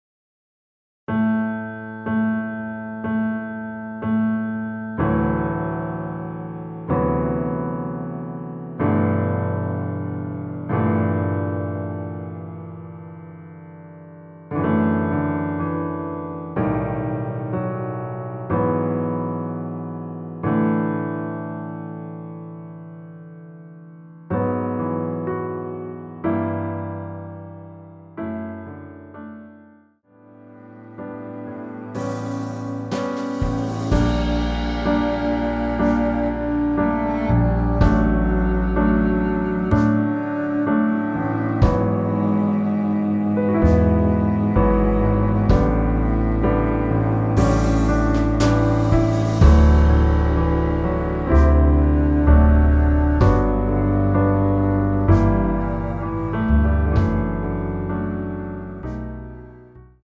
원키에서(-5)내린 MR입니다.
D
앞부분30초, 뒷부분30초씩 편집해서 올려 드리고 있습니다.
중간에 음이 끈어지고 다시 나오는 이유는